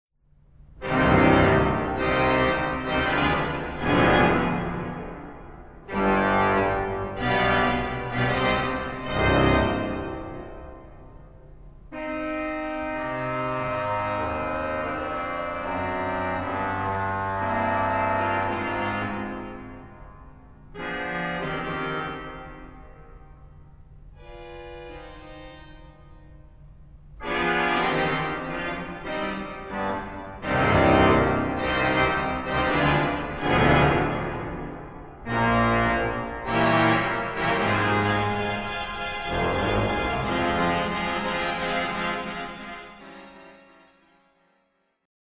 Dialogue de claviers (du plus fort au plus doux : Bombarde, Grand-orgue, Positif, Echo) ; Récit de Trompette (du clavier de Bombarde) accompagné par les Flûtes du Positif et de la Pédale ; au centre de la pièce, Récit de Trompette d’Echo, bientôt en dialogue avec celle de Bombarde ; conclusion sur les Flûtes.
Positif : Trompette, Cromorne, Clairon
G.O. : Cornet, 1ère et 2e Trompette, Clairon
Pédale : Bombarde, Trompette, Clairon